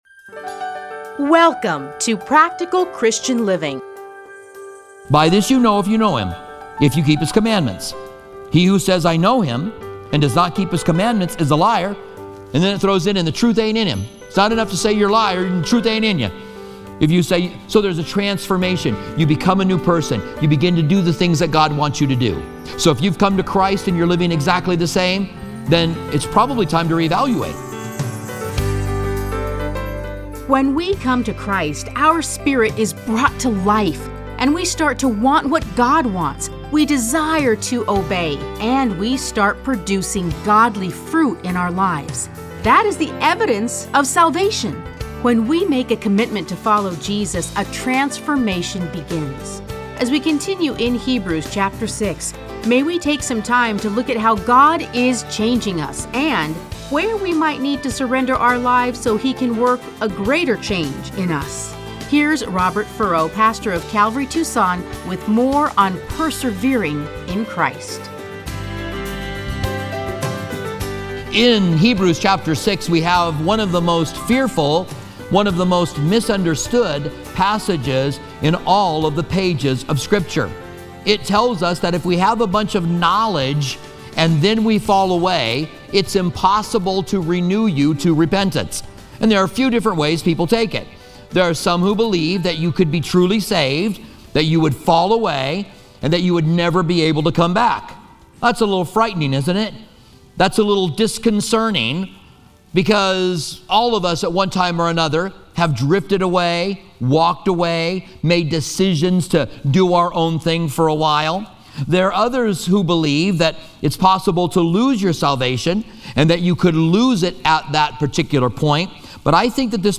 Listen to a teaching from Hebrews 6:1-20.